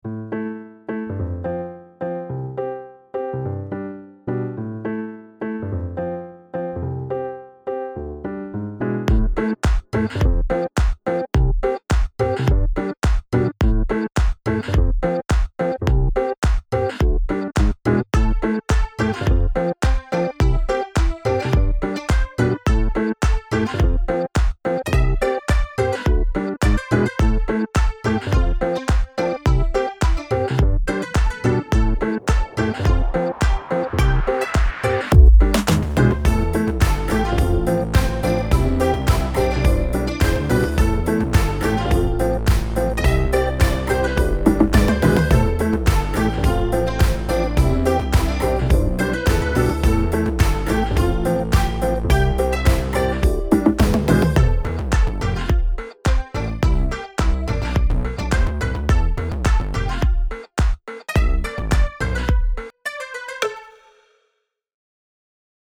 Created with GarageBand in about 2 days. 100% by me.
sounds like something i would fall asleep too